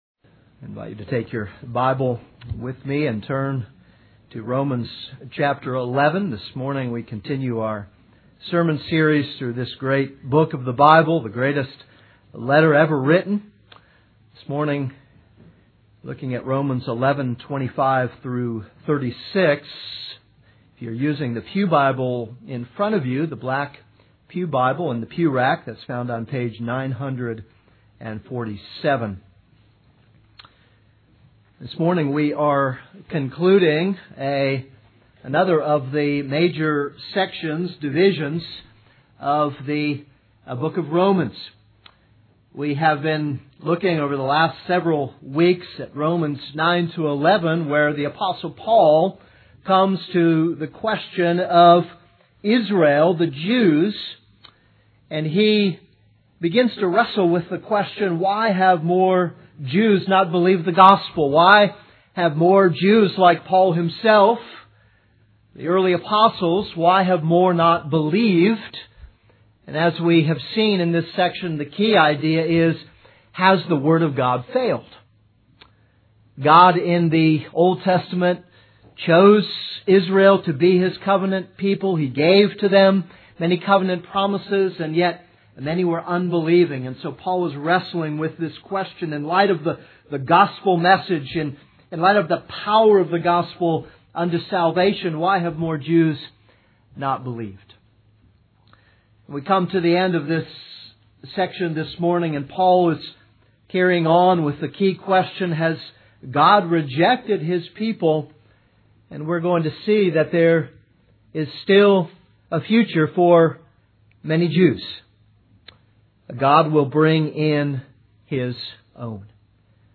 This is a sermon on Romans 11:25-36.